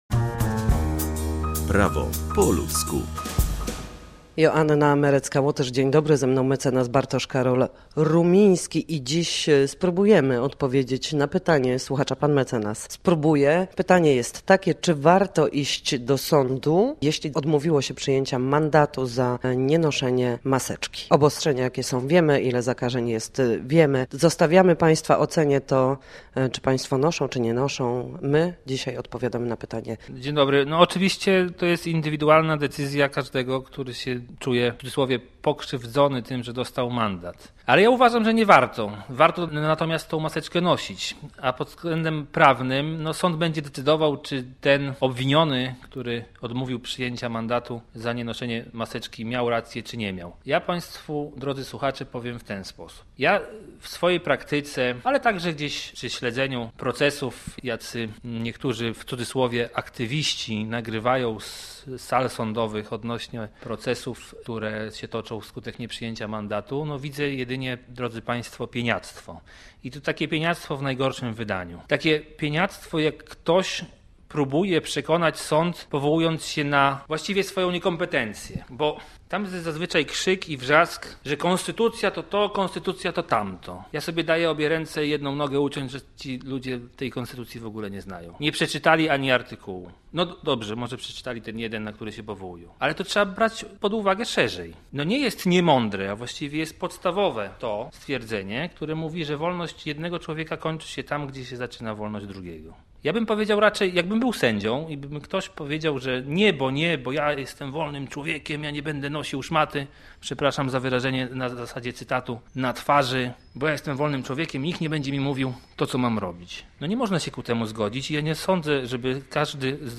W każdy piątek o godzinie 7:20 na antenie Studia Słupsk przybliżamy Państwu meandry prawa. Nasi goście, prawnicy, odpowiadają będą na jedno pytanie dotyczące zachowania w sądzie, podstawowych zagadnień prawniczych czy pobytu na sali sądowej.